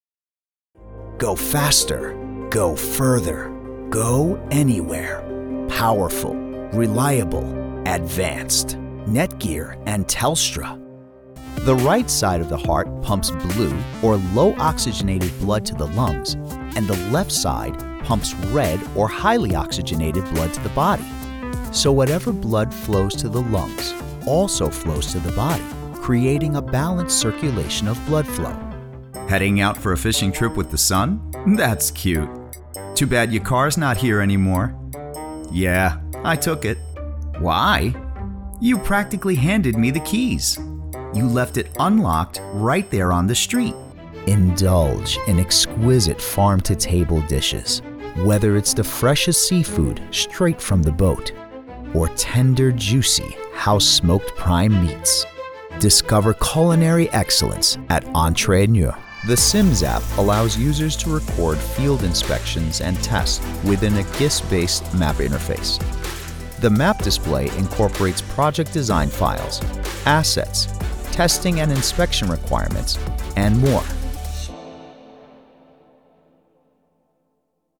Educated male with a warm, rich, authoritative voice
Demos
English - USA and Canada
Voice Age
Young Adult
Middle Aged